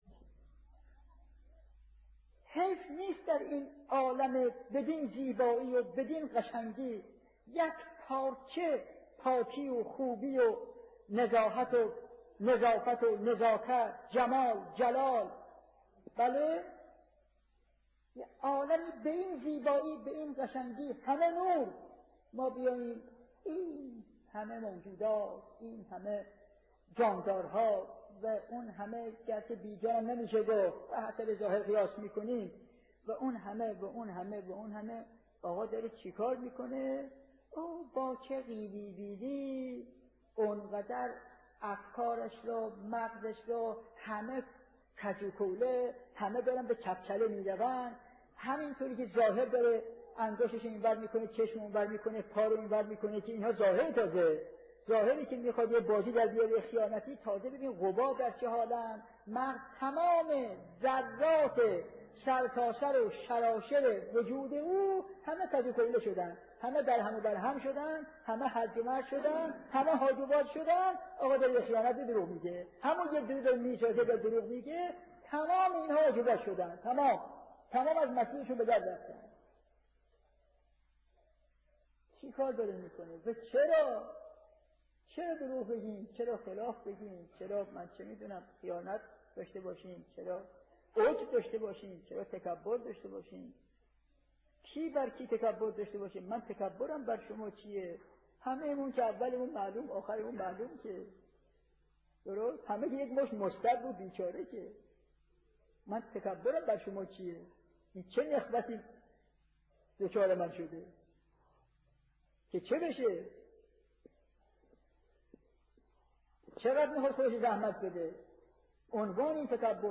به گزارش خبرگزاری حوزه، مرحوم علامه حسن زاده آملی در یکی از سخنرانی های خود به موضوع «ظاهر انسان پریشان با ذات محتاج به هدایت» اشاره کردند که تقدیم شما فرهیختگان می شود.